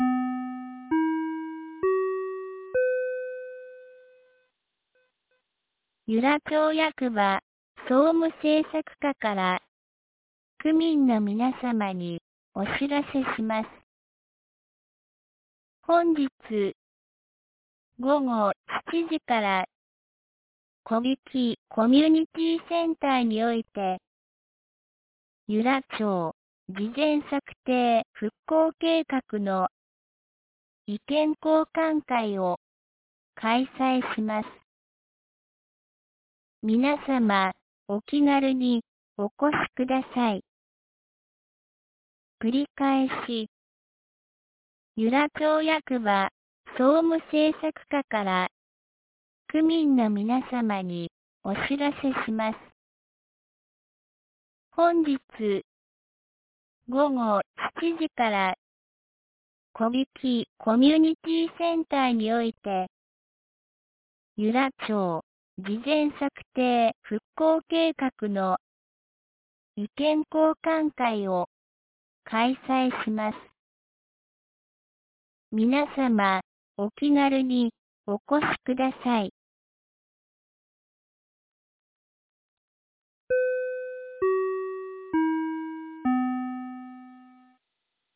2022年11月05日 12時26分に、由良町から小引地区へ放送がありました。